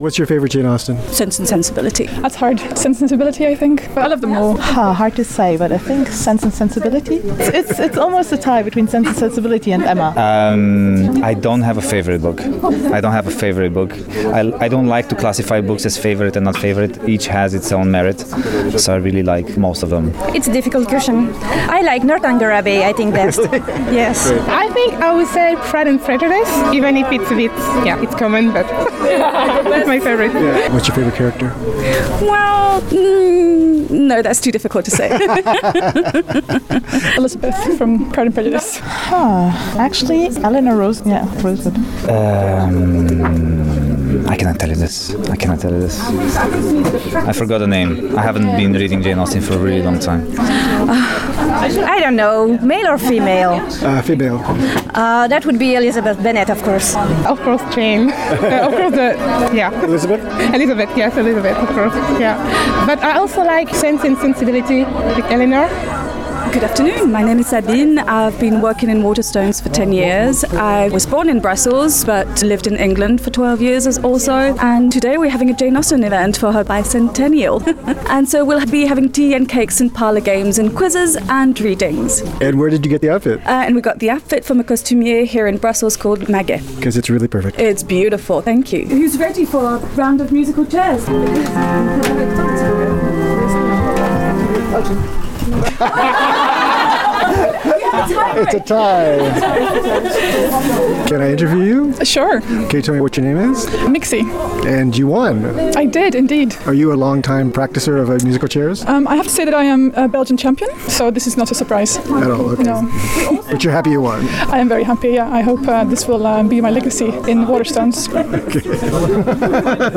Jane Austen may be an English writer but her appeal is universal as was obvious at Waterstone's Tea with Jane Austen event celebrating the bicentenary of of her death with people from Spain, Bulgaria, Romania, Belgium, the US and the UK and others, in attendance. We spoke with attendees and organizers and among other things found out the origin of cucumber sandwiches.